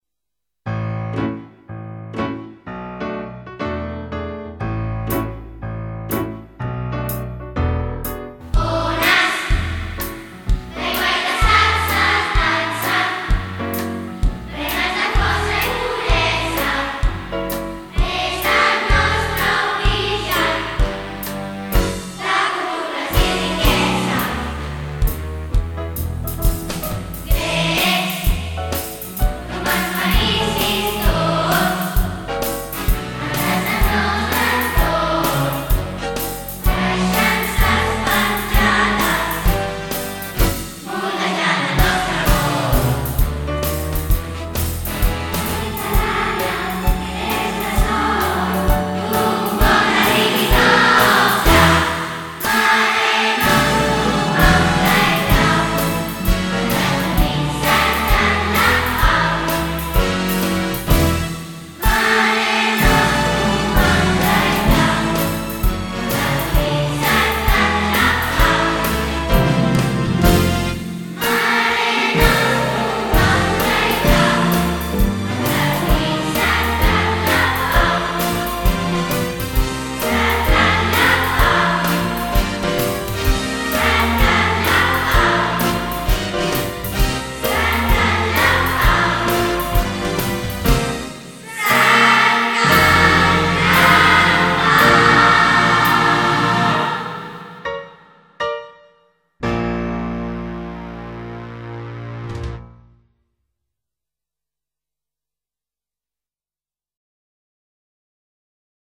Cant de Fi de Curs
Amb aquest cant dedicat al Mar de Mars – Mediterrània, hem acomiadat el curs 2010-11, en el nou edifici del Mar i Cel.